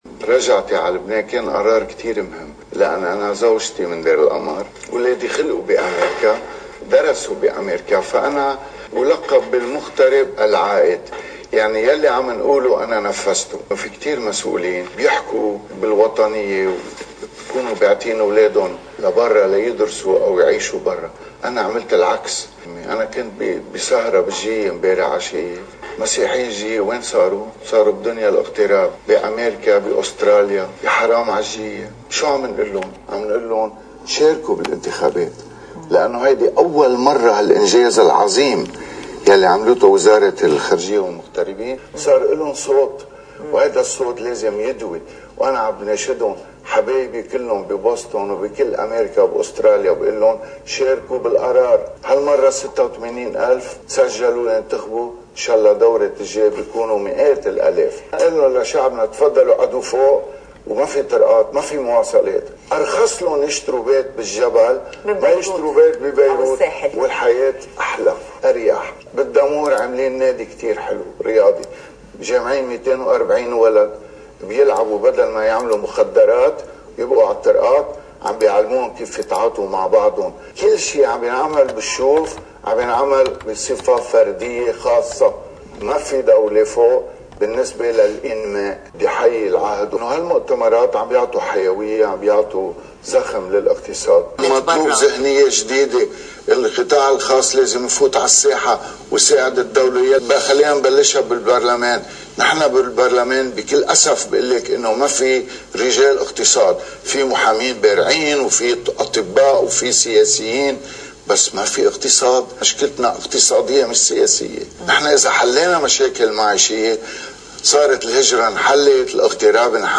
مقتطف من حديث المرشّح عن المقعد الماروني فريد البستاني، لقناة الـ”LBC”: (نيسان 6 – 2018)